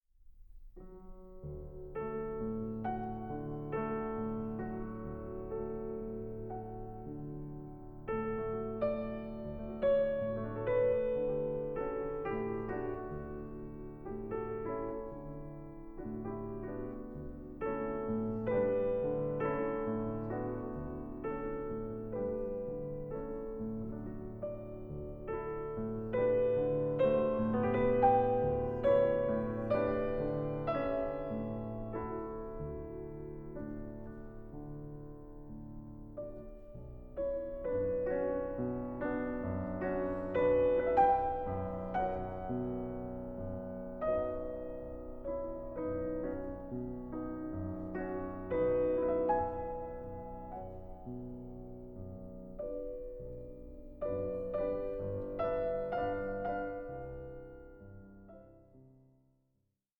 No. 1 in D Minor, Andante